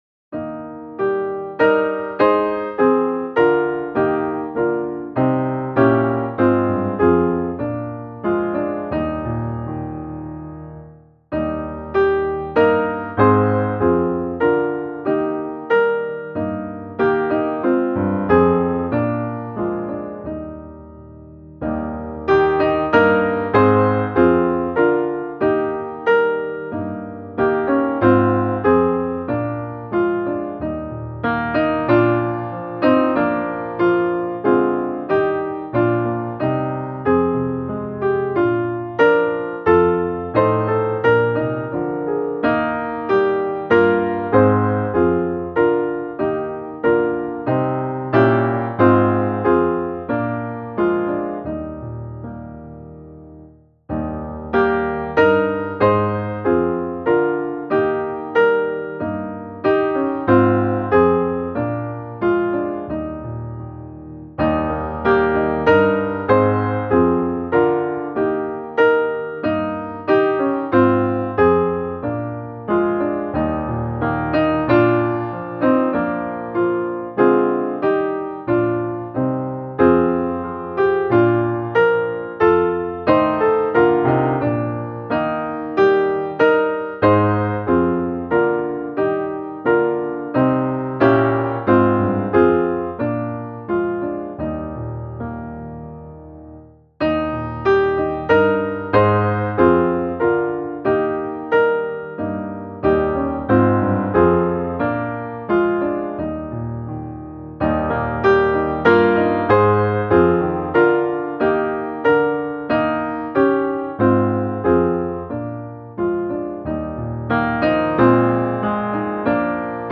Lammets folk och Sions fränder - musikbakgrund